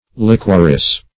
Liquorice \Liq"uor*ice\ (l[i^]k"[~e]r*[i^]s), n.